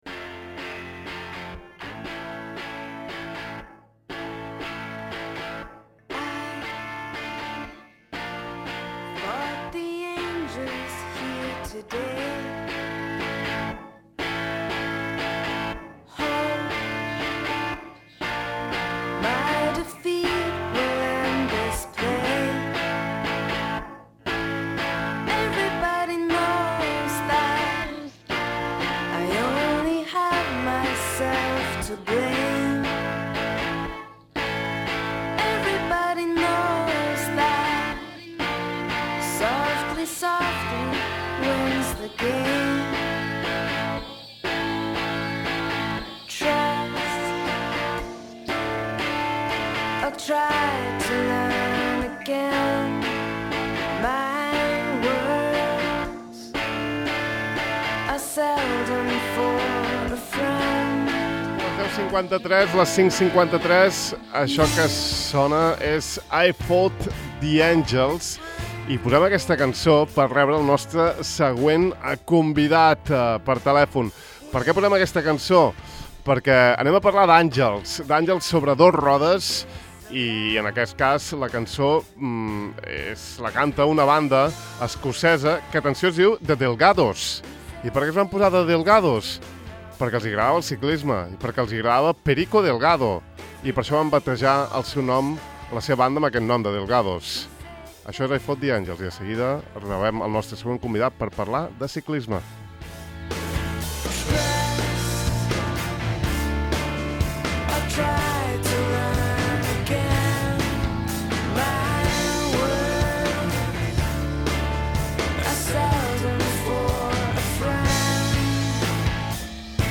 Per aquells que voleu sentir parlar tant de Bugno i Séan Kelly com de Kurt Cobain, aquesta és la vostra entrevista: